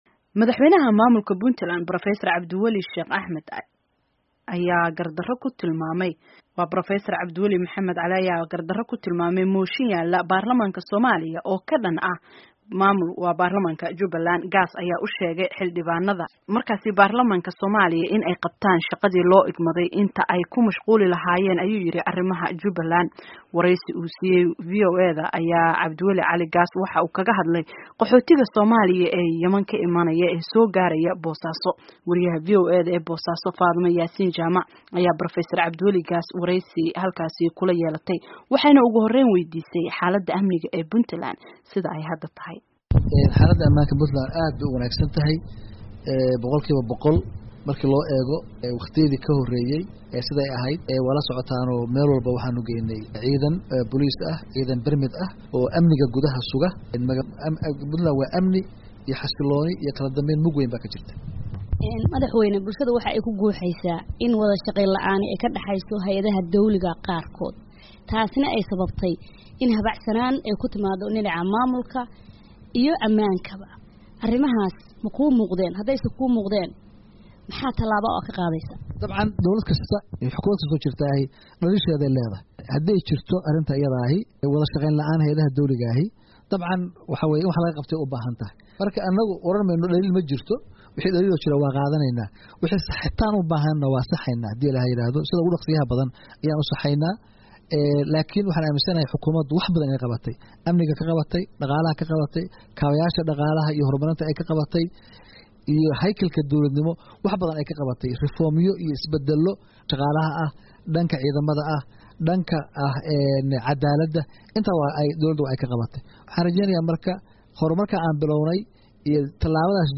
Dhageyso Wareysiga Cabdiwali Gaas